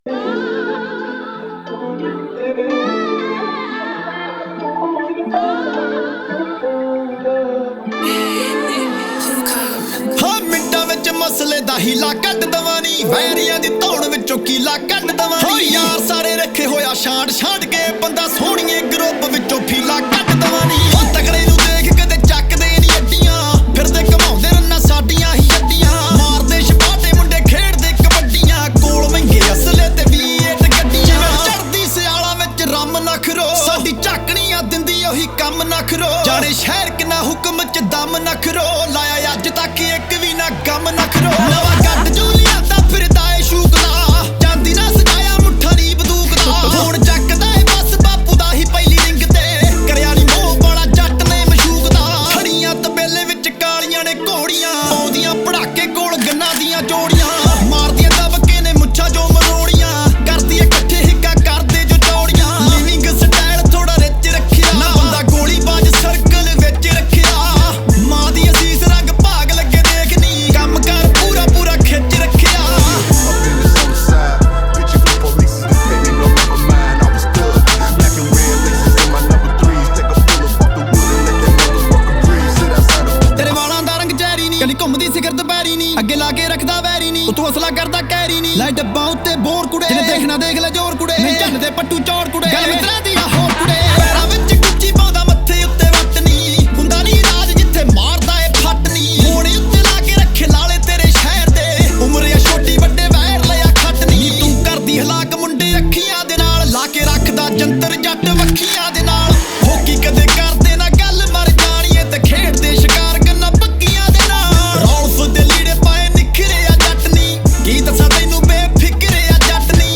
Category: Punjabi Singles